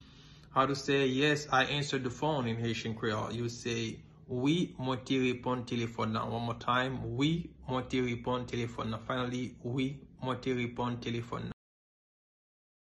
Pronunciation:
Yes-I-answered-the-phone-in-Haitian-Creole-Wi-mwen-te-reponn-telefon-lan-pronunciation.mp3